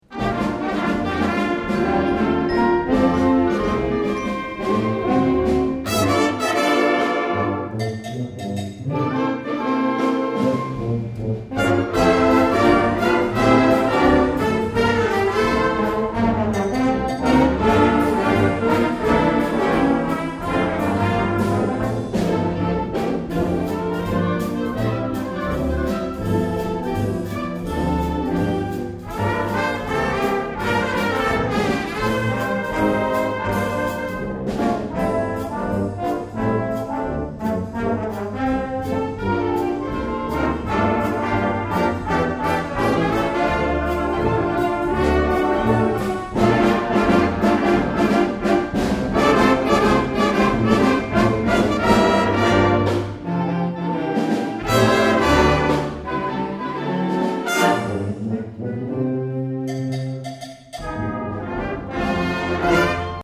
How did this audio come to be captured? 2008 Summer Concert June 22, 2008 - San Marcos High School